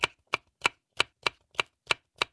• 声道 單聲道 (1ch)